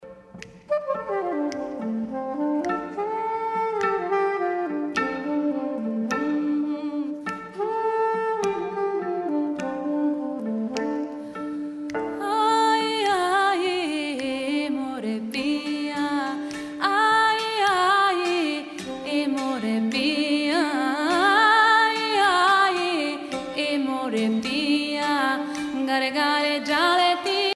Concert a Brugges Festival, Belgique
Pièce musicale inédite